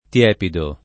tiepido [